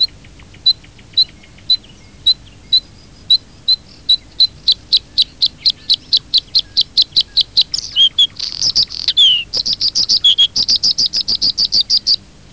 Варакушка (Luscinia svecica)
Luscinia-svecica.wav